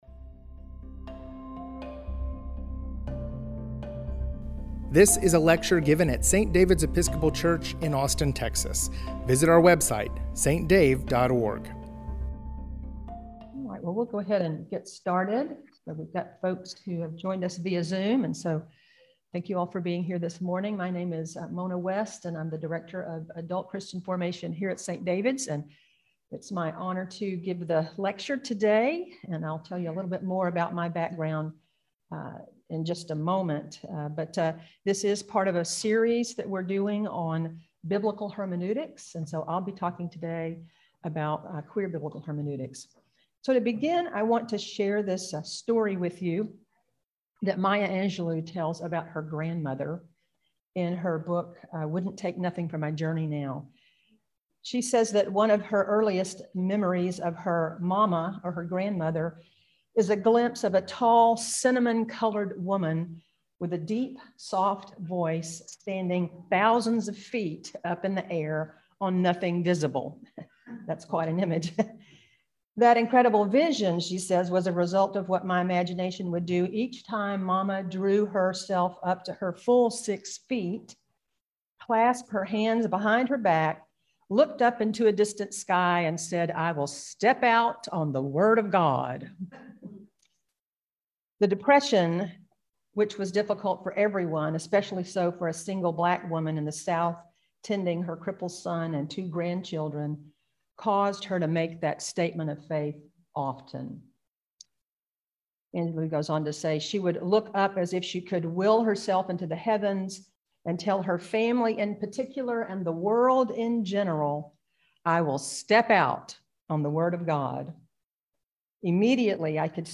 Biblical Hermeneutics Lecture Series: Queer Biblical Hermeneutics